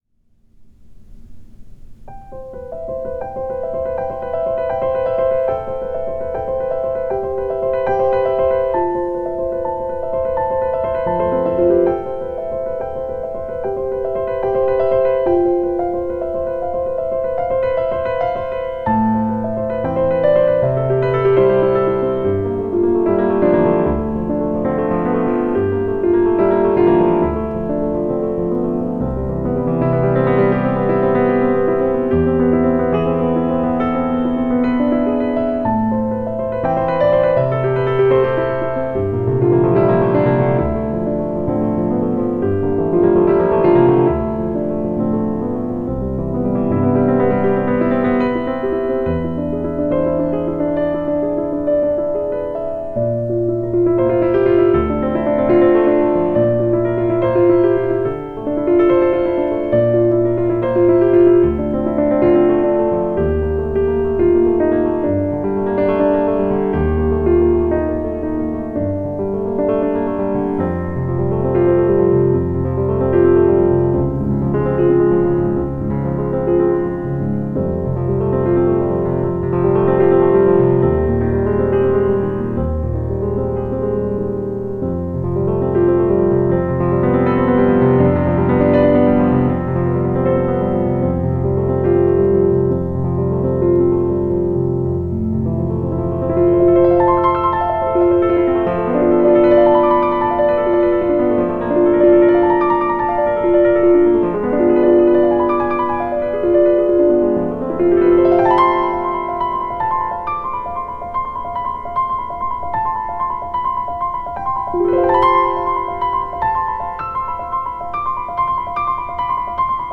Audioaufnahme vom Konzert in der Carnegie Hall, Juni 2020